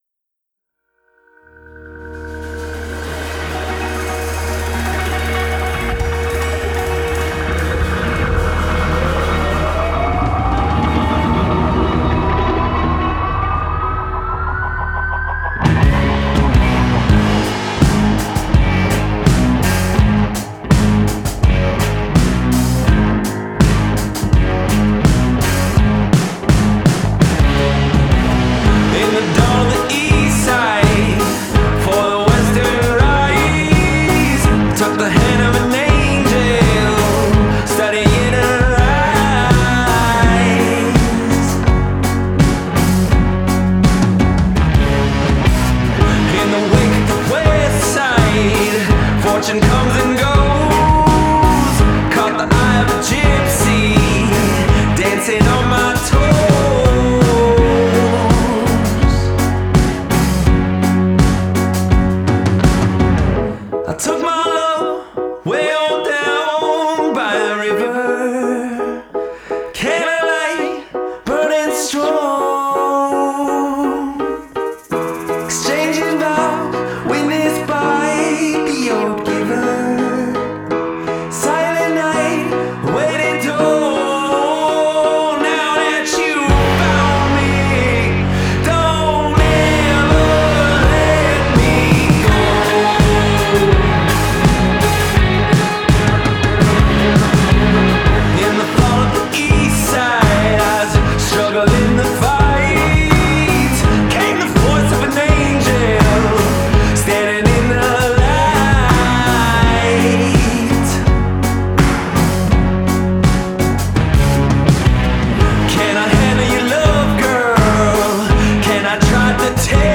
Western Canadian indie music mix